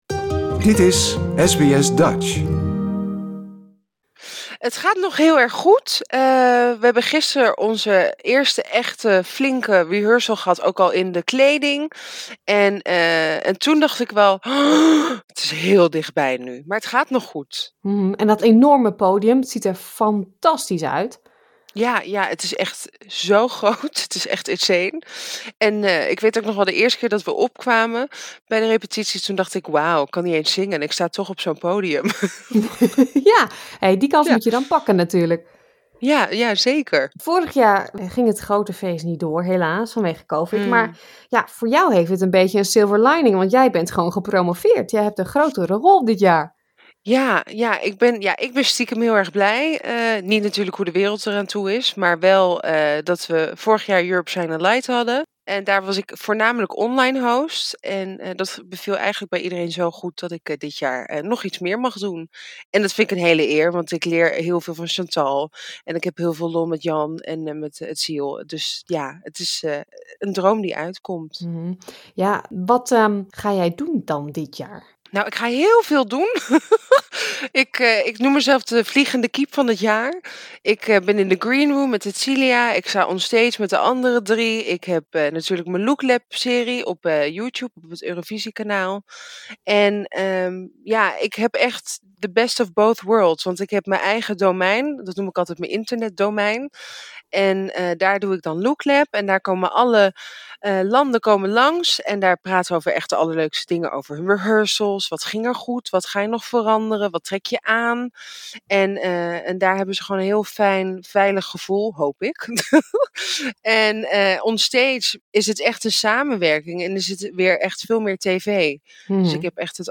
SBS Dutch sprak met Nikkie over het de grootste presentatieklus van haar carriere, LookLab en uiteraard make up.